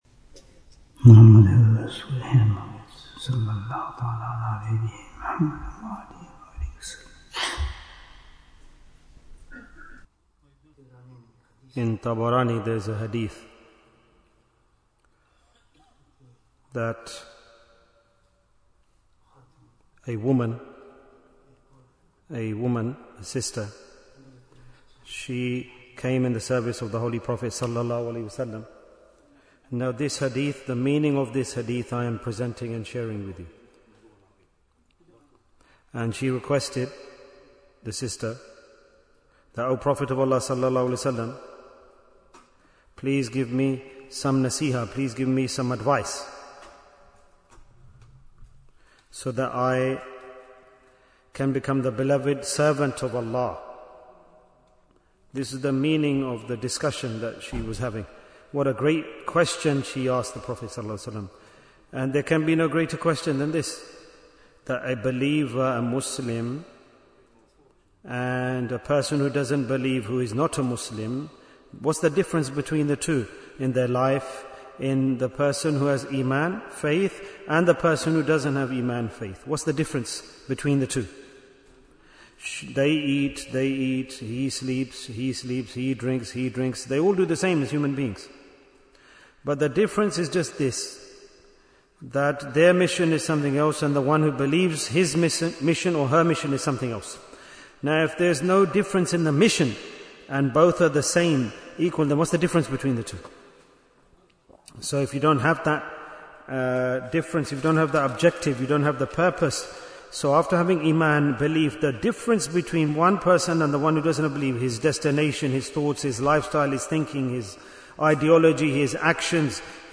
Jewels of Ramadhan 2026 - Episode 37 Bayan, 60 minutes16th March, 2026